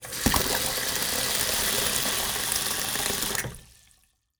Sink Fill 07
Sink Fill 07.wav